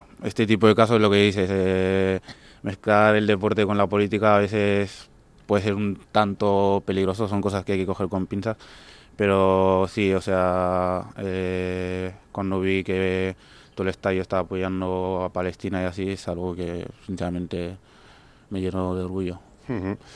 Adama Boiro en una entrevista con Radio Popular em Lezama / RADIO POPULAR - HERRI IRRATIA
Durante su entrevista en Oye Cómo Va, el futbolista del Athletic Adama Boiro se ha referido al homenaje a Palestina que se ha vivido recientemente en San Mamés.